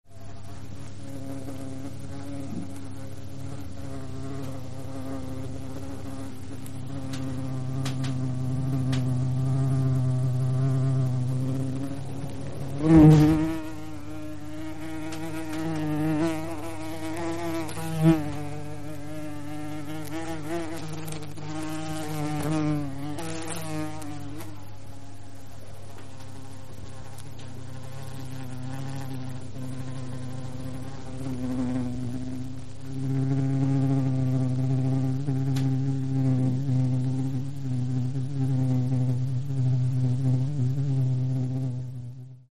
جلوه های صوتی
دانلود صدای زنبور 2 از ساعد نیوز با لینک مستقیم و کیفیت بالا